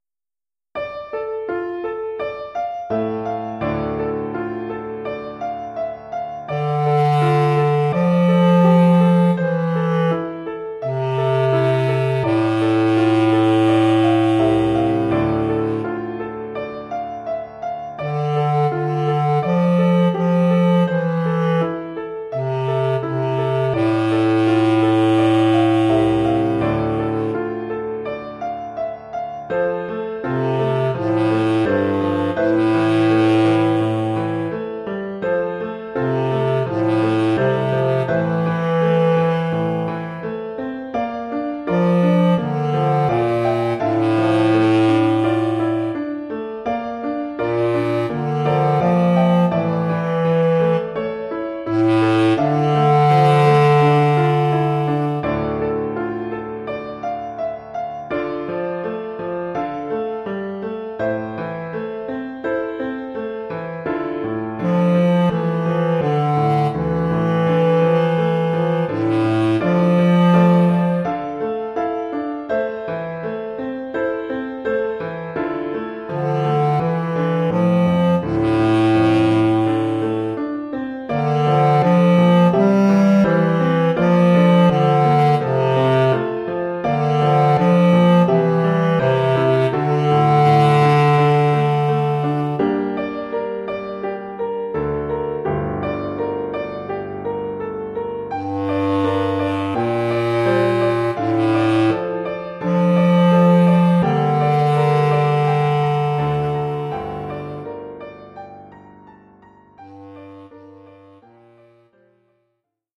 Formule instrumentale : Clarinette basse et piano
Oeuvre pour clarinette basse et piano.